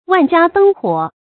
成语繁体 萬家燈火 成语简拼 wjdh 成语注音 ㄨㄢˋ ㄐㄧㄚ ㄉㄥ ㄏㄨㄛˇ 常用程度 常用成语 感情色彩 中性成语 成语用法 偏正式；作谓语、宾语、定语；形容城市夜晚的景象 成语结构 偏正式成语 产生年代 古代成语 成语正音 万，不能读作“mò”。